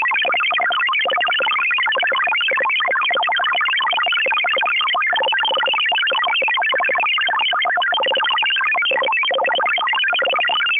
• Enigma Designation XPB is a custom 16-tone MFSK mode said to have originated from Russian Intelligence and Foreign Ministry stations: